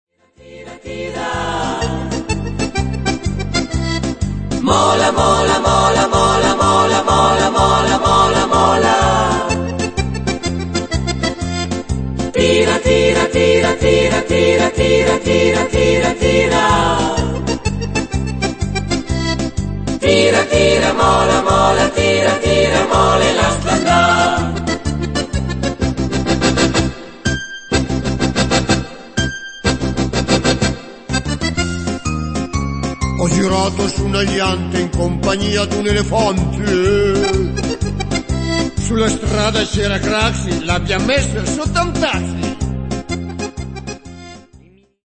marcetta